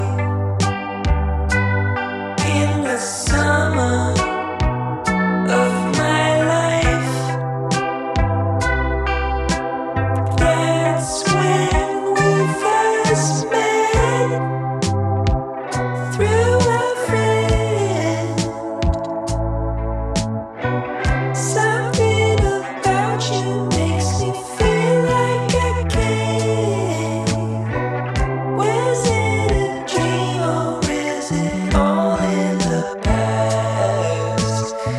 Нестандартные аккорды и неожиданные переходы
2019-08-09 Жанр: Альтернатива Длительность